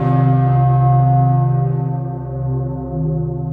bell.aiff